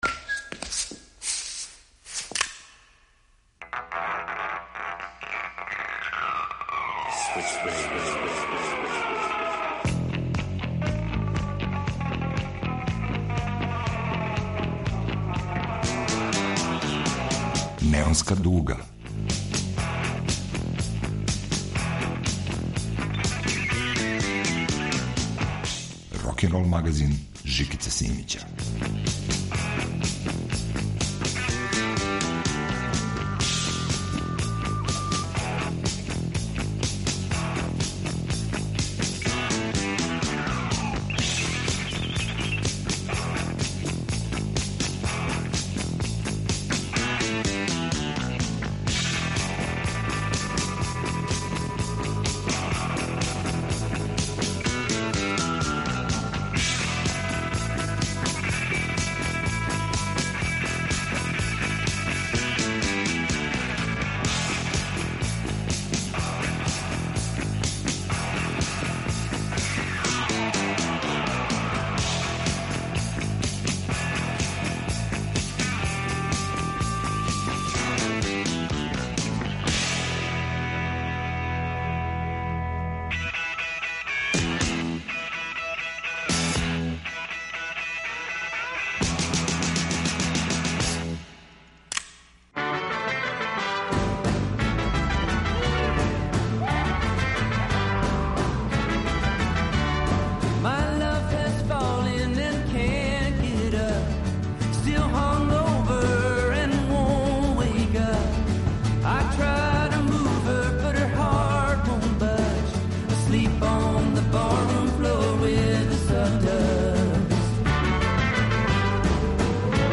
Крећемо на музичко путовање од Хаитија и Кубе, преко Малија, до Истре и Београда. Рокенрол глобус се весело врти у егзотичним, диско и рок ритмовима.